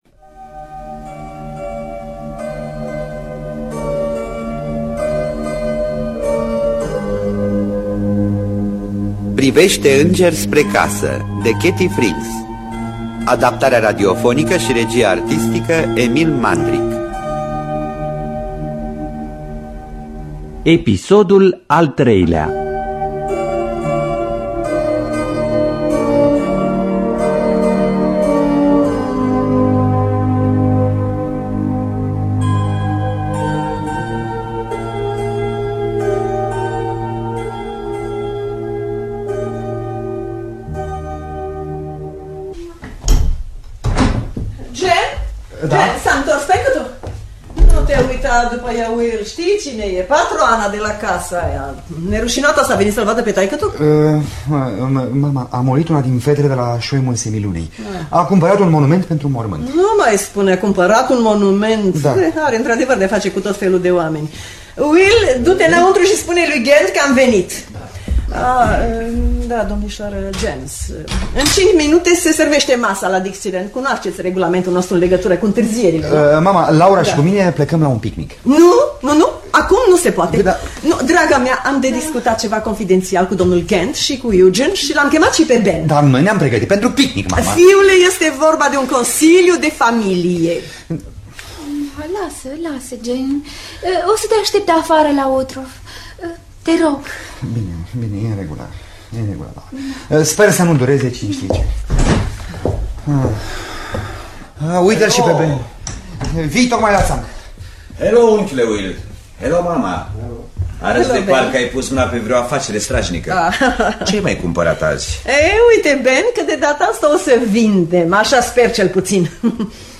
Adaptarea radiofonică de Emil Mandric.